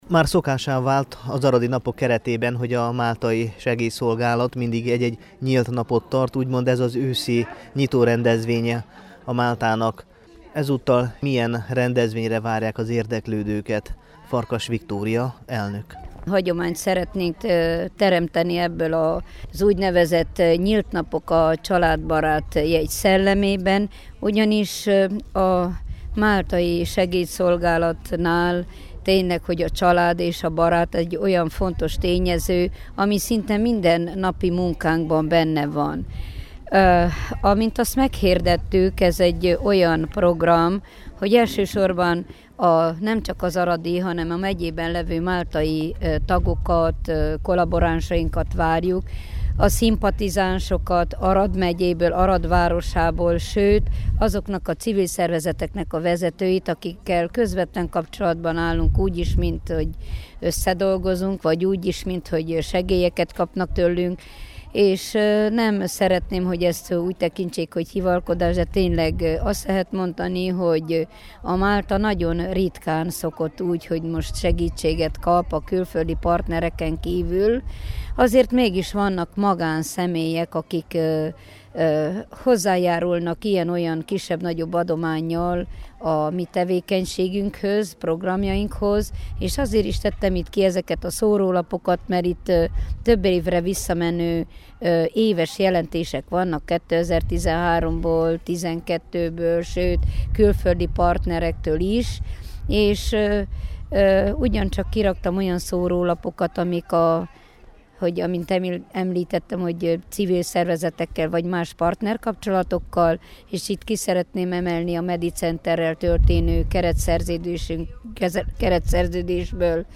összeállítást a Temesvári Rádió számára